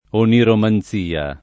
vai all'elenco alfabetico delle voci ingrandisci il carattere 100% rimpicciolisci il carattere stampa invia tramite posta elettronica codividi su Facebook oniromanzia [ oniroman Z& a ] (meglio che oneiromanzia ) s. f.